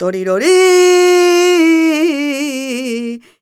46b04voc-a#m.aif